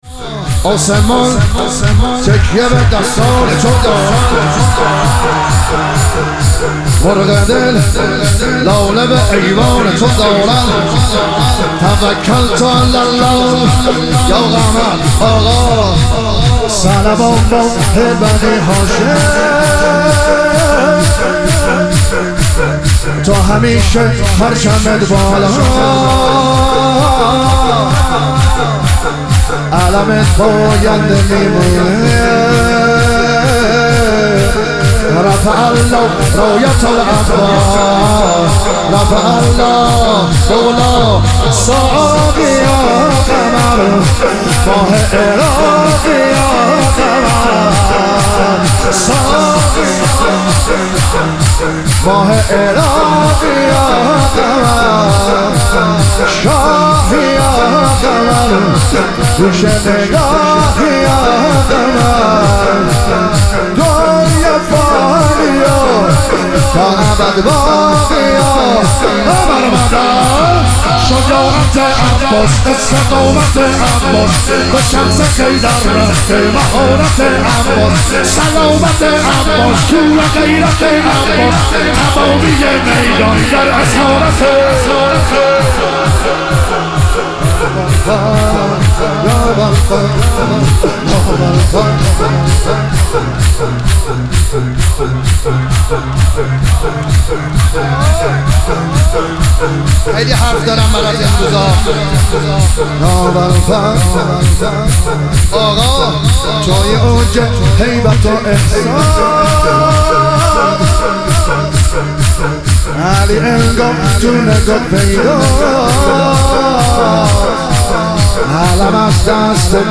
مشهد الرضا - شور